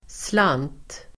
Uttal: [slan:t]